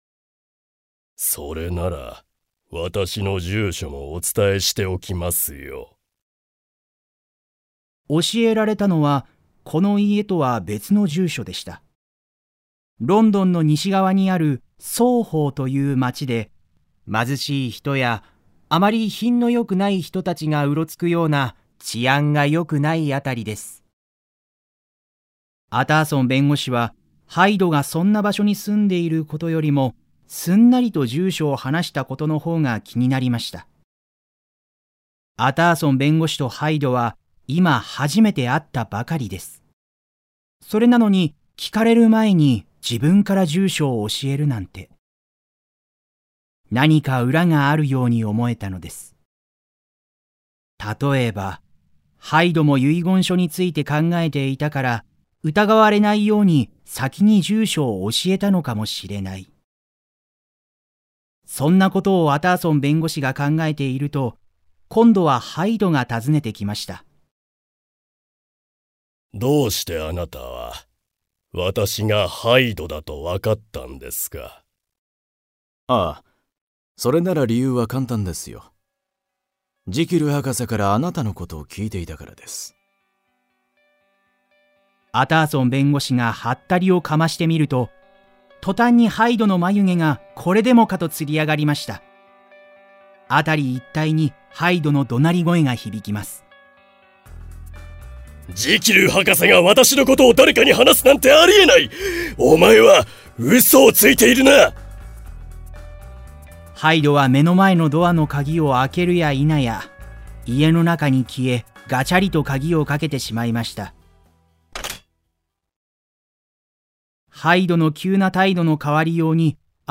[オーディオブック] ジキルとハイド（こどものための聴く名作37）